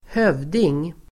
Uttal: [²h'öv:ding]